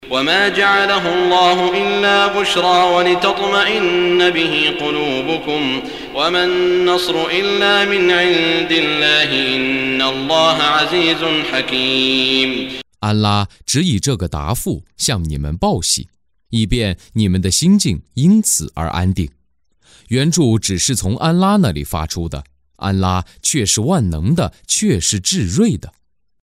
中文语音诵读的《古兰经》第（安法里）章经文译解（按节分段），并附有诵经家沙特·舒拉伊姆的朗诵